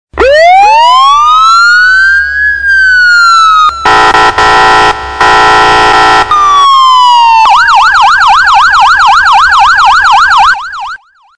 На этой странице вы можете скачать и послушать онлайн различные звуки сирены скорой помощи.
Громкий звук сирены автомобиля скорой помощи